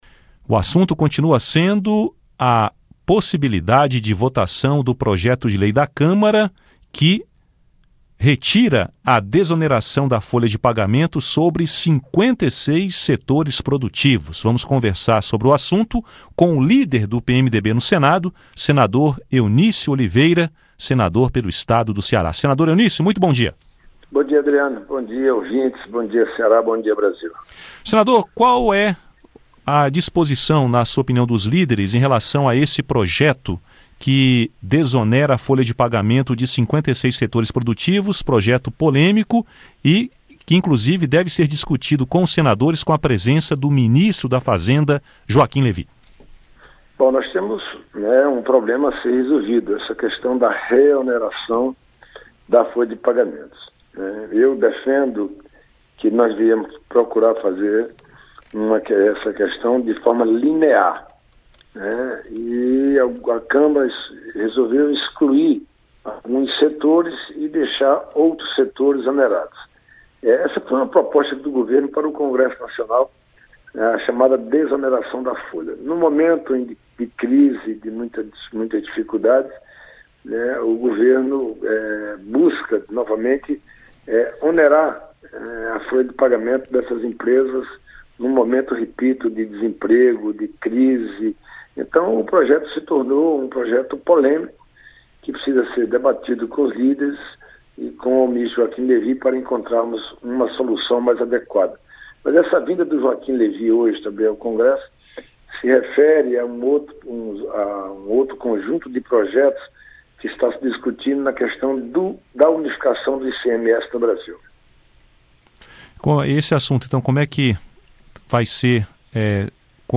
Entrevista
Em entrevista à Radio Senado, Eunício fala também sobre unificação do ICMS e regularização de recursos depositados fora do Brasil.